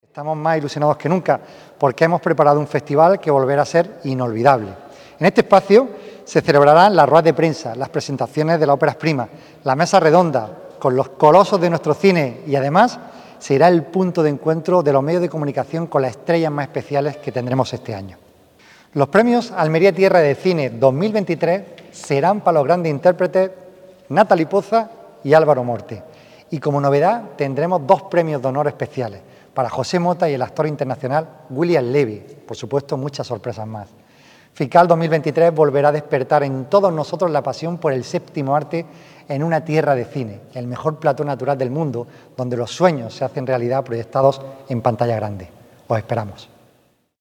El Festival Internacional de Cine de Almería ha presentado su programación en Madrid, en la sede de Cosentino de la capital de España, en un acto presentado por la periodista Isabel Jiménez
JAVIER-A.-GARCÍA-FICAL-2023.mp3